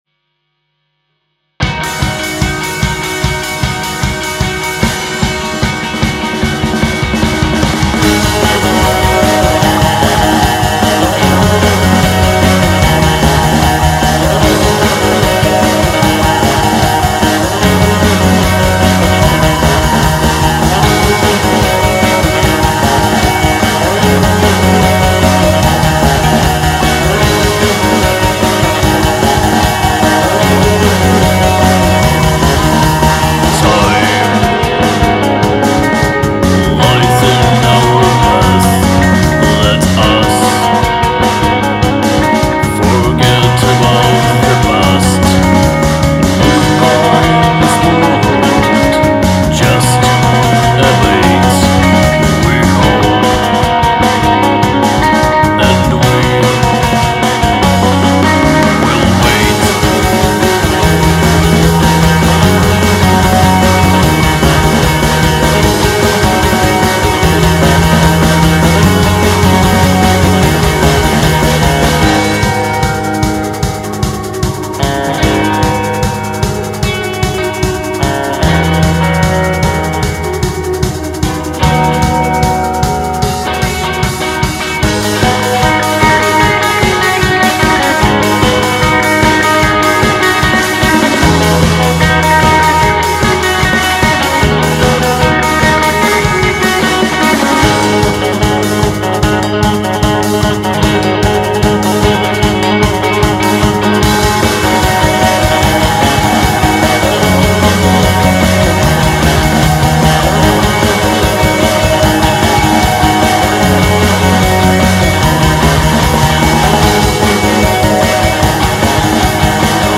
Post Punk Style (More music inside)